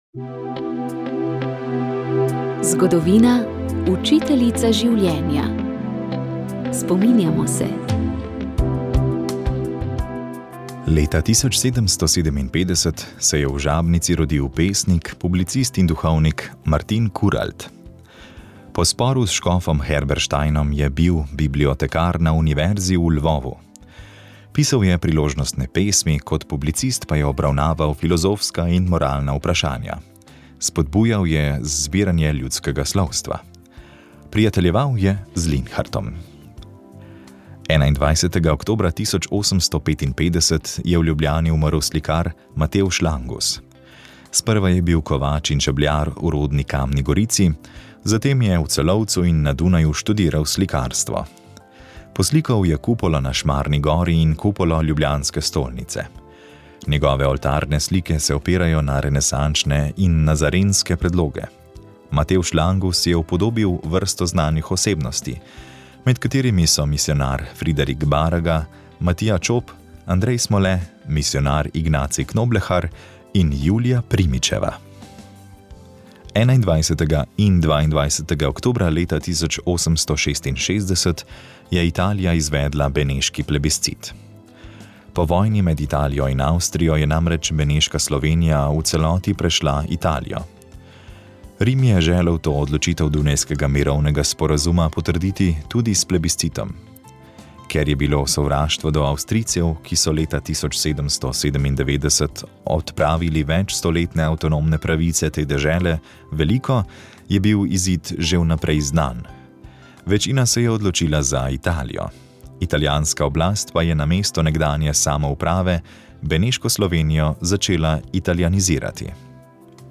Molili so radijski sodelavci.